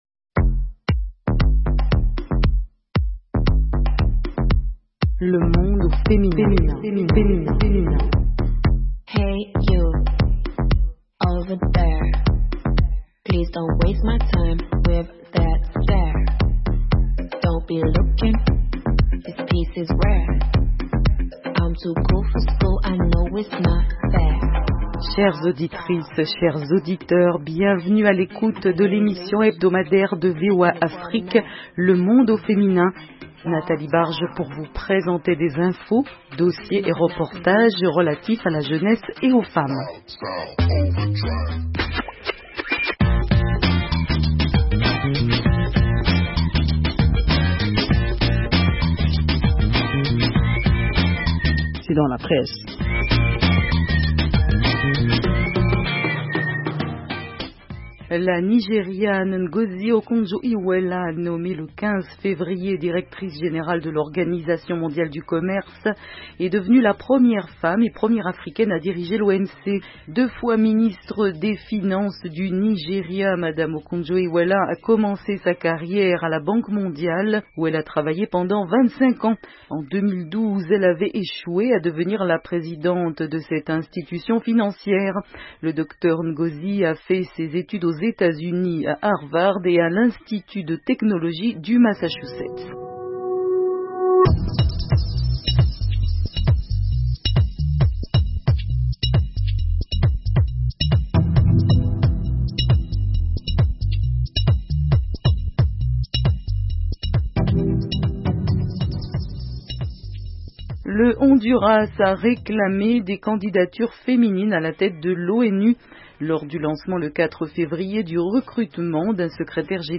émission interactive